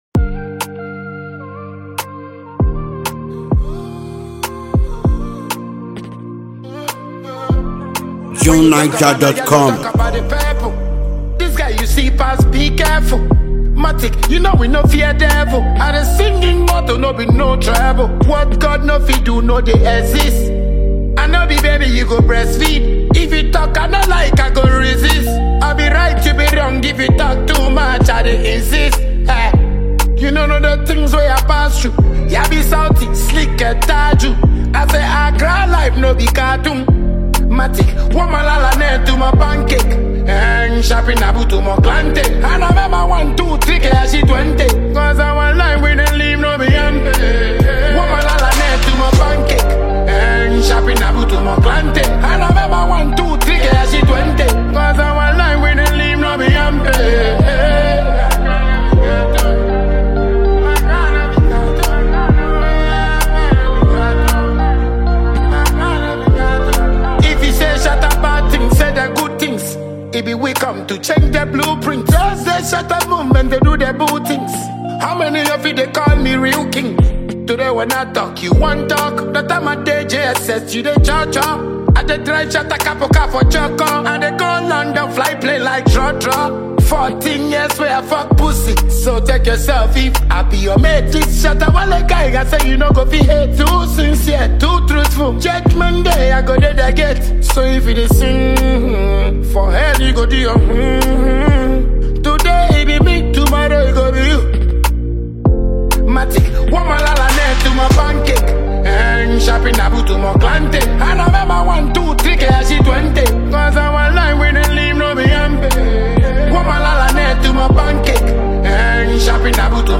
spontaneous and searing, scorching lyrical volume